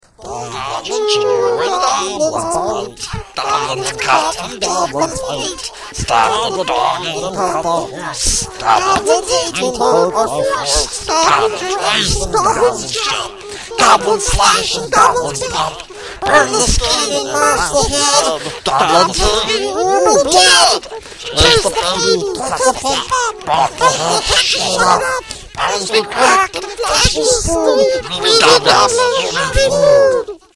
As blood pools around its head, the raucous sounds of a strange song begin, chanted in broken common with shrill, scratchy voices: